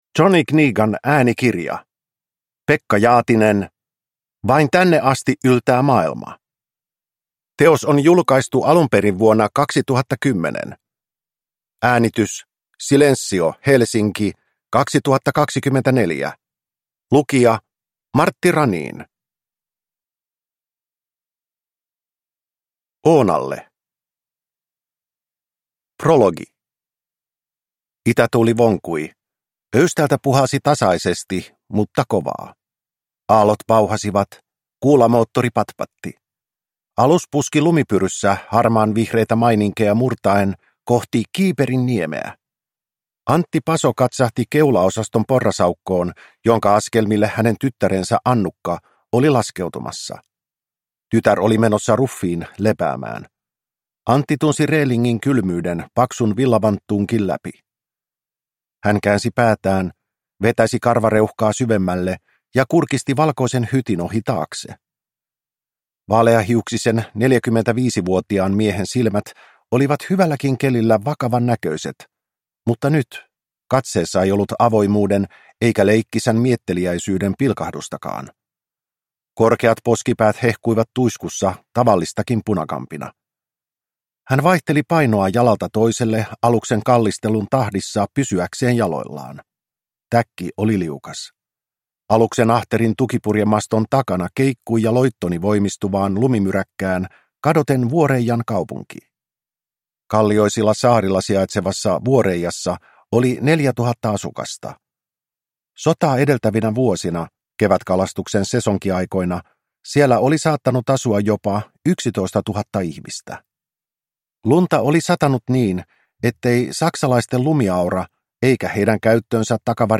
Vain tänne asti yltää maailma – Ljudbok